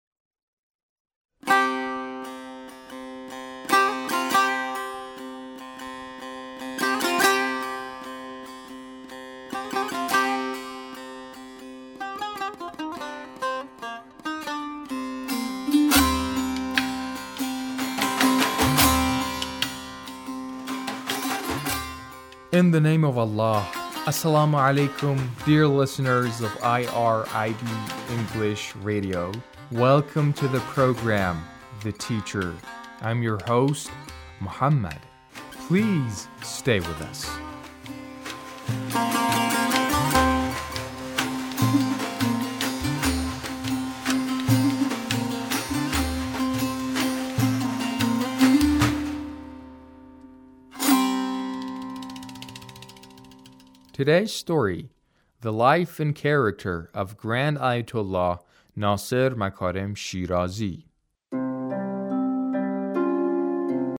A radio documentary on the life of Ayatullah Makarem Shirazi